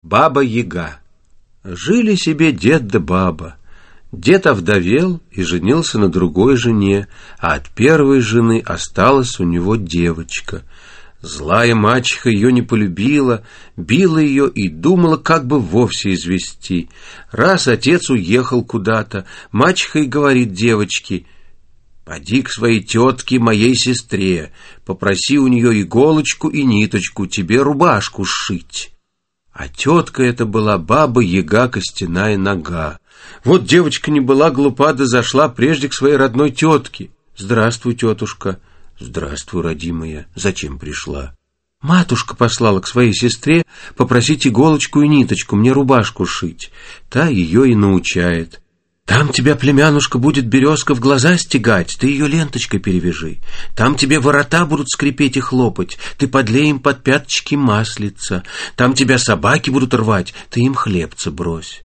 Русские народные сказки (читает Вениамин Смехов) –